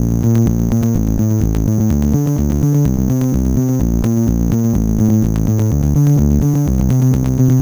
Crackly Sixteens Bb 126.wav